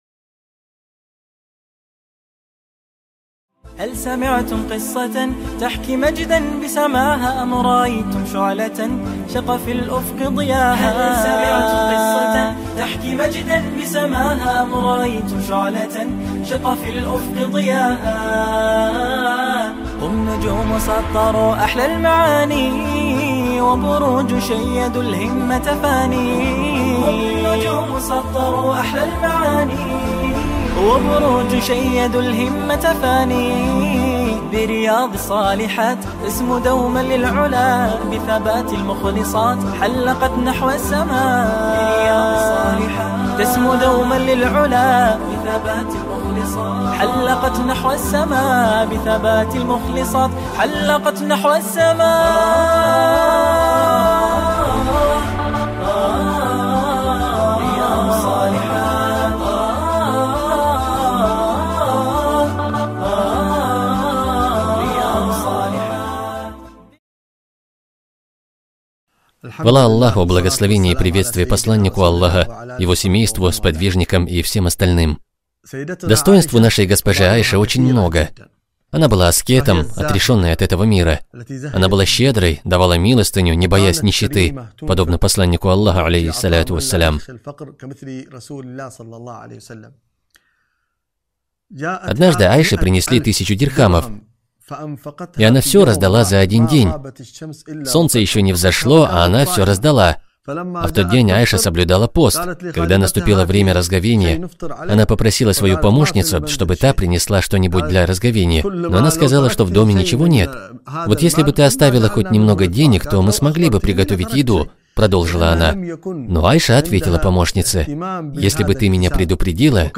Совершенные женщины | Цикл уроков для мусульманок